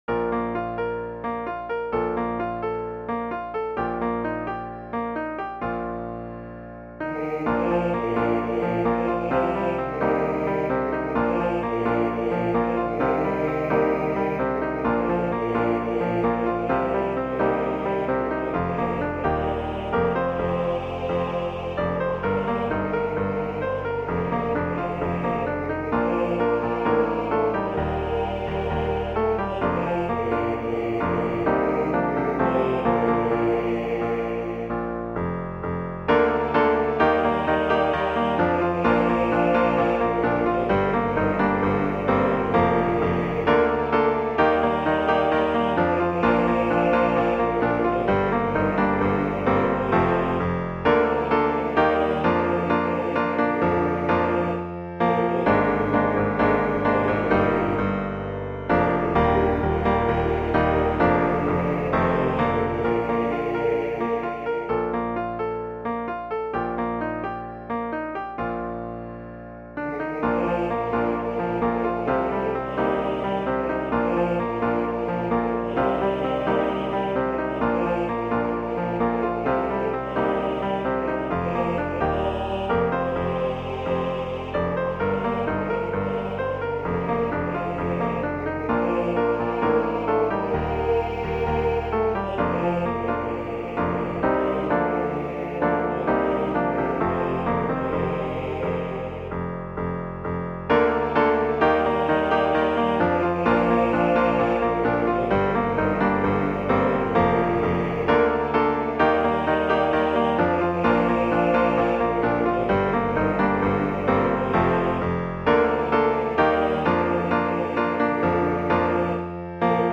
I Will Serve the Lord 2-part mens choir